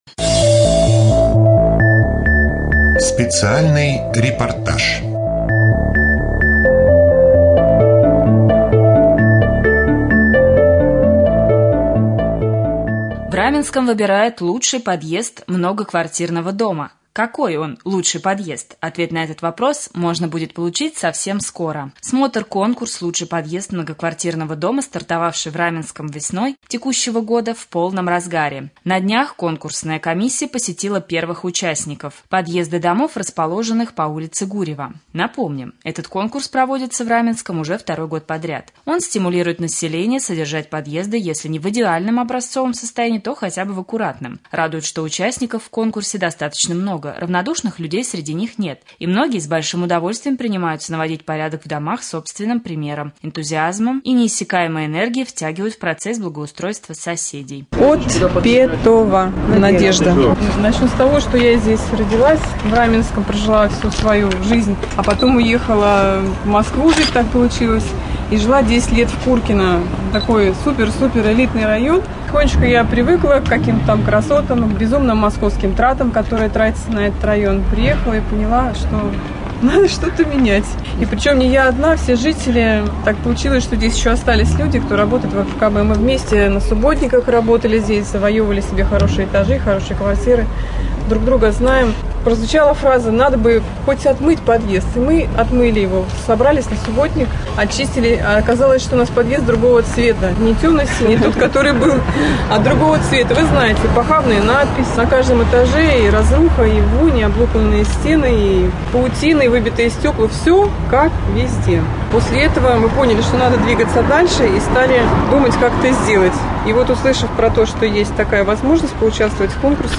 4.Рубрика «Специальный репортаж». В Раменском выбирают лучший подъезд многоквартирного дома.